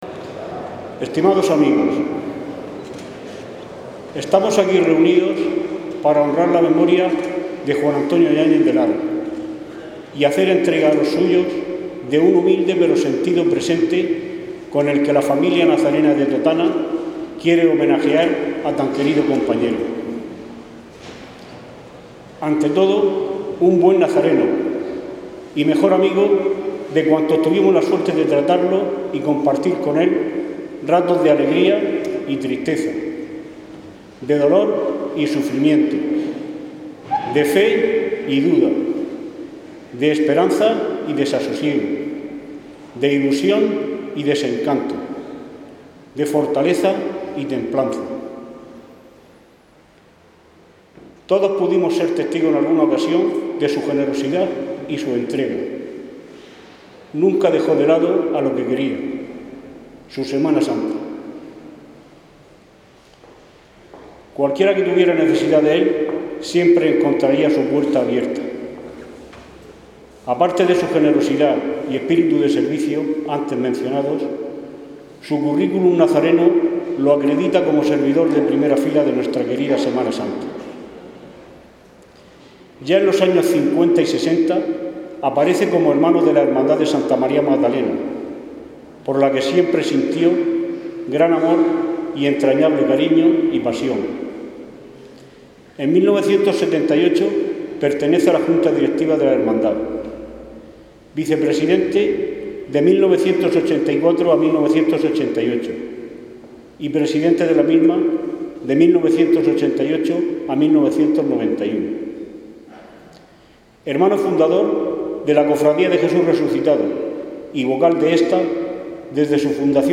Semana Santa de Totana